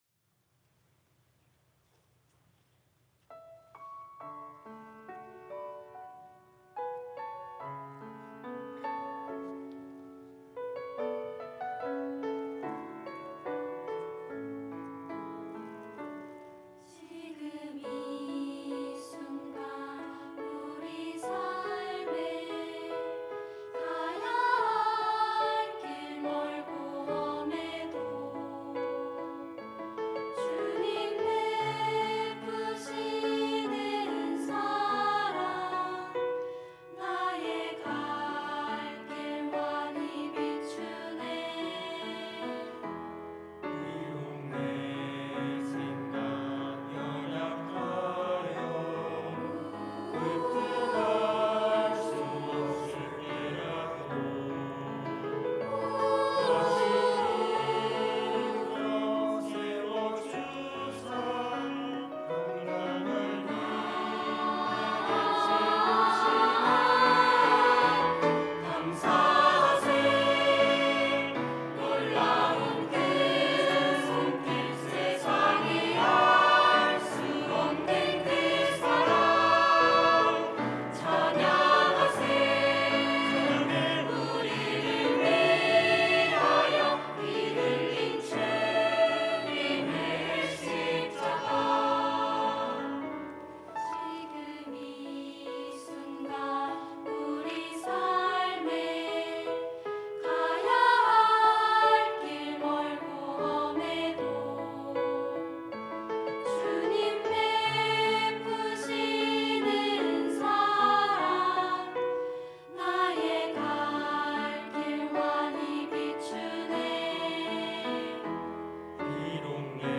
특송과 특주 - 주님은 내 삶에 힘 되시네
청년부 카이노스 찬양대